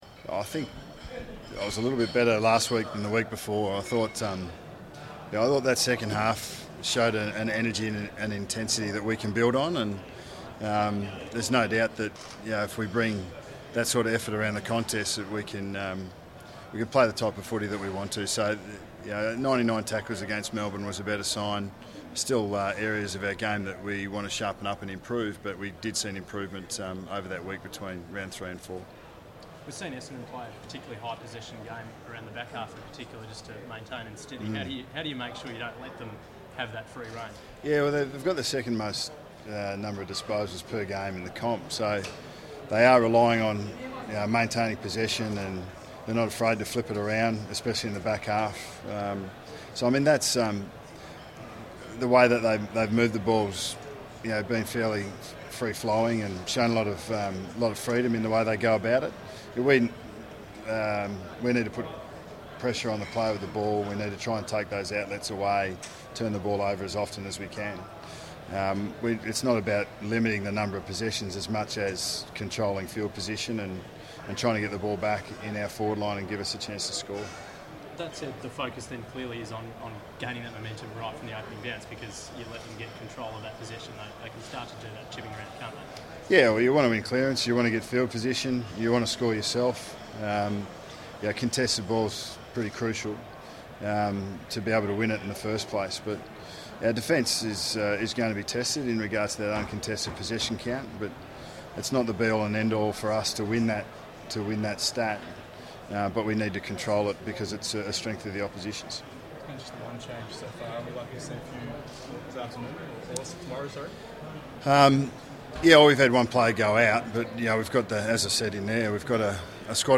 Press Conference: Nathan Buckley
Listen to coach Nathan Buckley address the media ahead of Collingwood's ANZAC Day clash with Essendon on Friday 22 April 2016 at the Glasshouse.